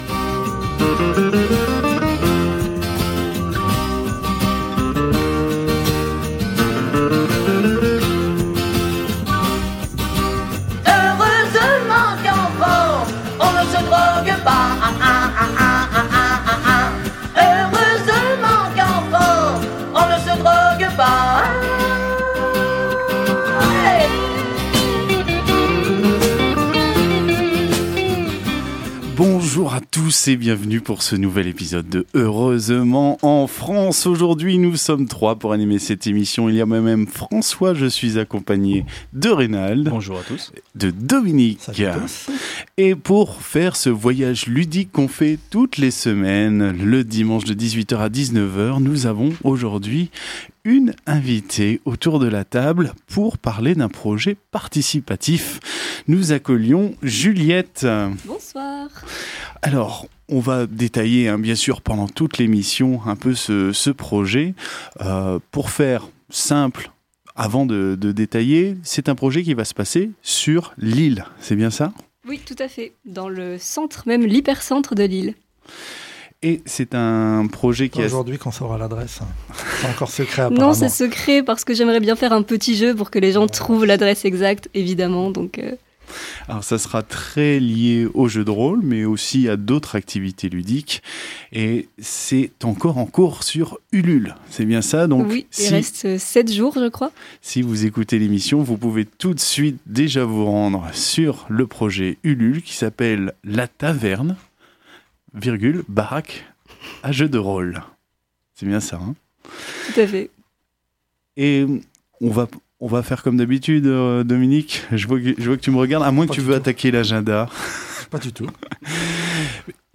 Au sommaire de cet épisode diffusé le 4 octobre 2020 sur Radio Campus 106.6 :